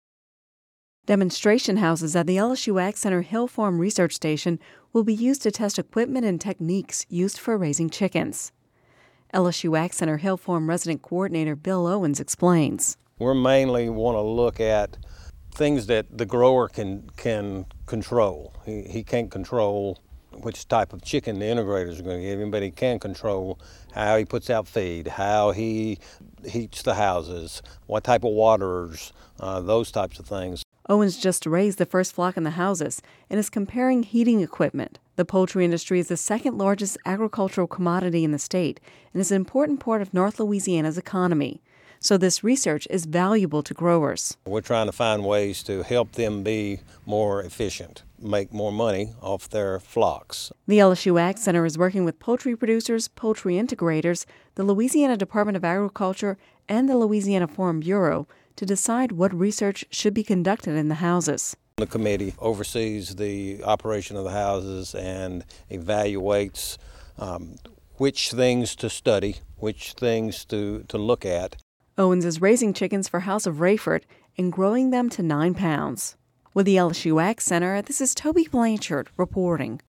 (Radio News 11/08/10) Demonstration houses at the LSU AgCenter's Hill Farm Research Station at Homer will be used to test equipment and techniques for raising chickens.